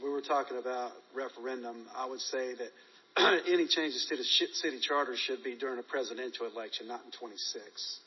He was speaking in the first council meeting since a judge days earlier ruled against his lawsuit challenging the city’s interpretation of the charter.